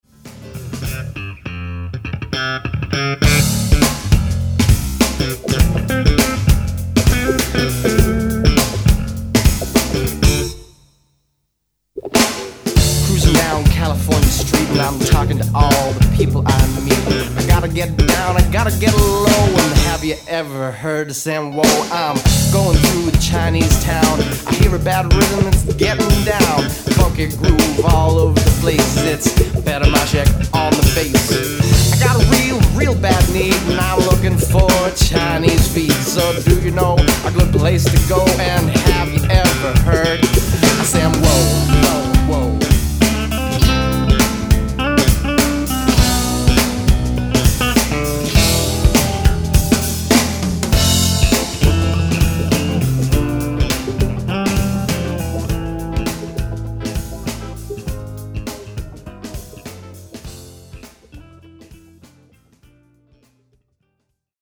bass solo
guitars
drums
keyboards
rap